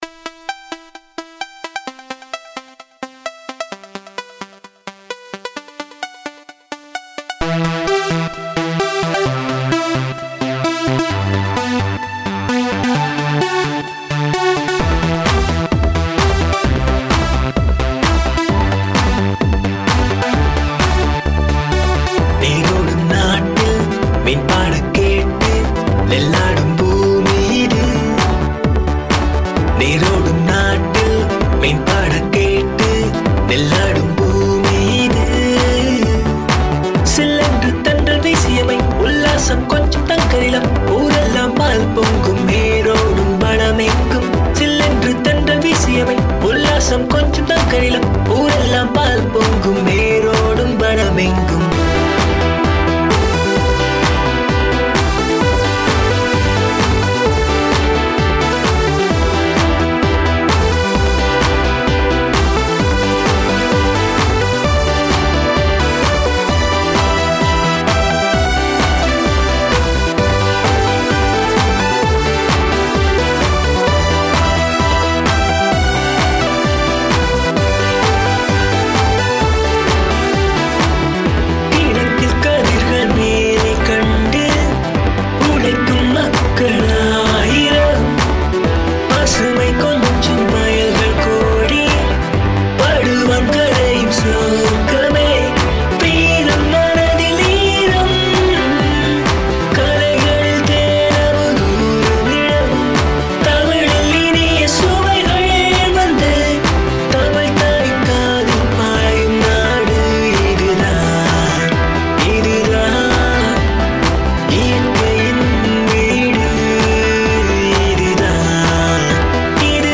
வித்தியாசமான நவீனத்துவ இசைவடிவத்தை பிரயோகித்து உள்ளமை குறிப்பிடத்தக்கதாகும்.